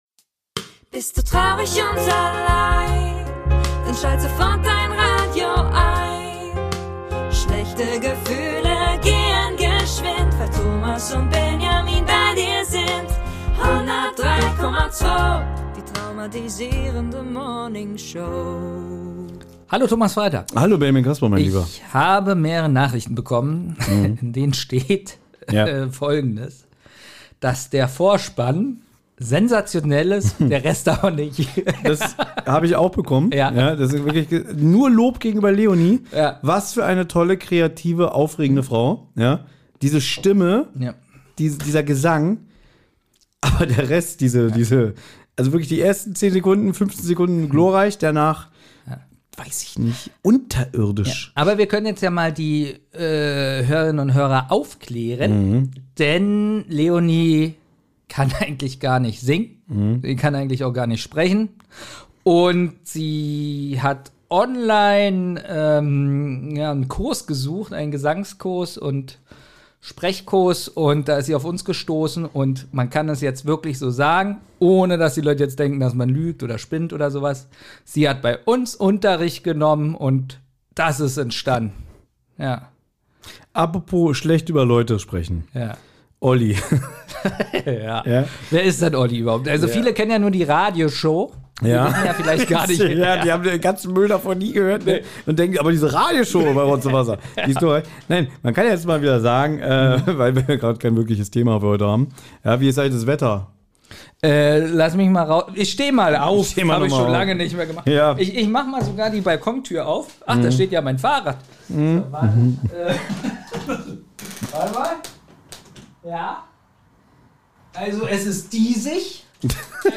In der heutigen Morningshow gibt es kein wirkliches Thema. Aber das ist natürlich kein Problem für die beiden Starmoderatoren